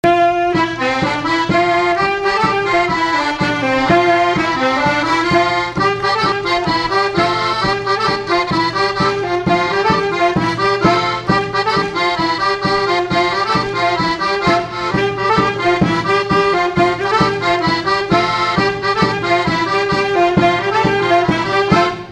Chaillé-sous-les-Ormeaux
Résumé instrumental
gestuel : danse
Pièce musicale inédite